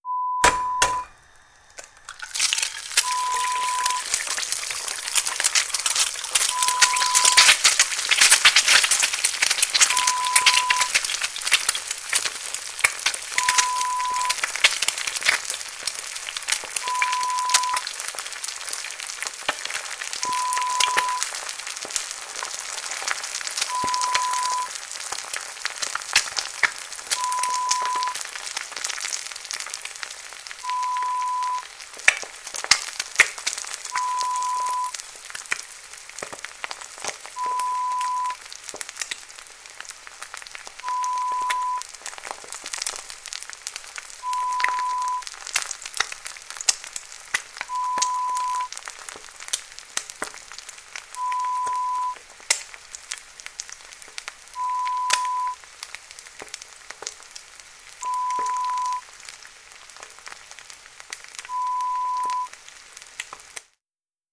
Fritando ovo = Friendo un huevo
Sonido del proceso de freir un huevo. Acompaña a dicho sonido un pitido espaciado y constante no relacionado con dicha actividad
sartén
Sonidos: Acciones humanas